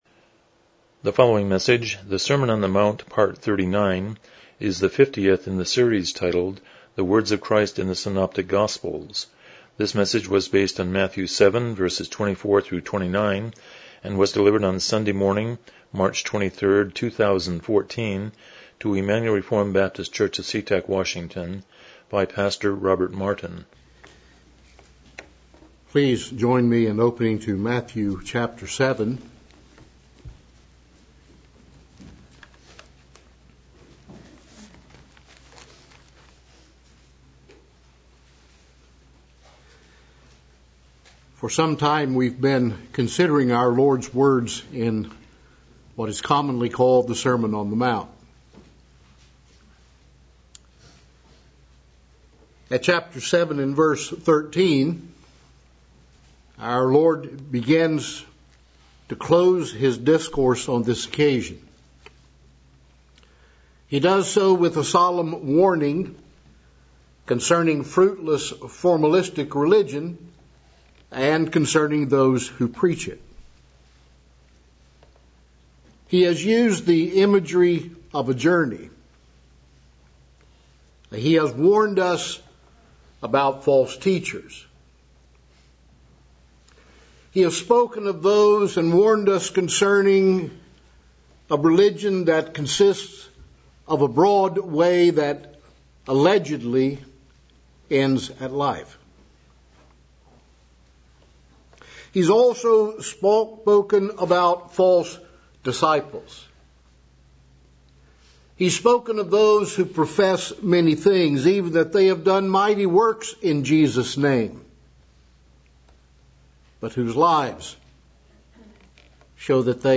Passage: Matthew 7:24-29 Service Type: Morning Worship